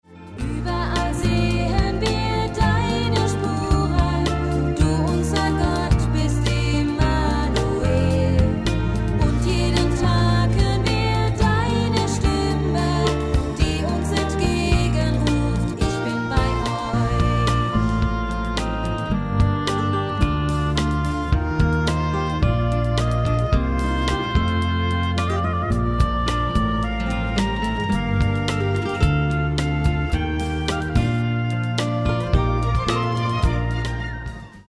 • ein Sampler moderner christlicher Pop-Songs
• eingängige Texte und Melodien